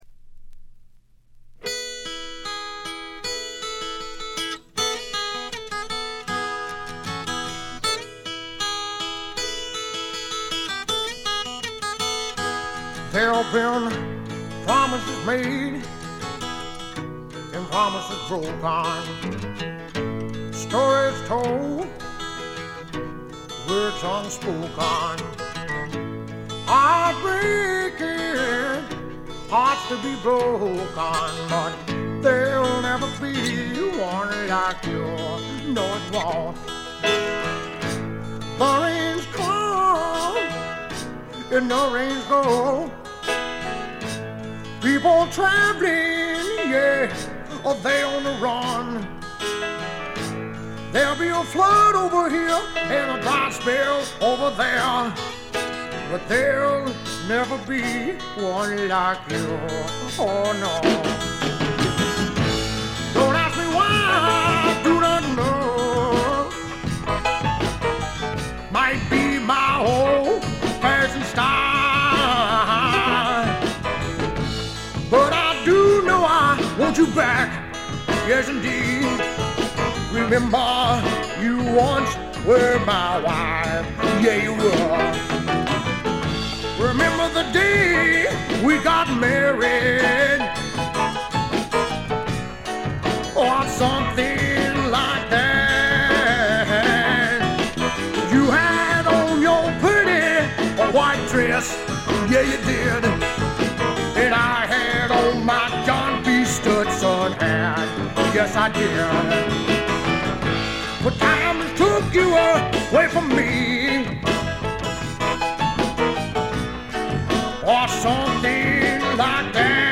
部分試聴ですが、ほとんどノイズ感無し。
肝心の音はといえば南部の湿った熱風が吹きすさぶ強烈なもの。
試聴曲は現品からの取り込み音源です。
Fiddle